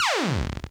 laser_shot.wav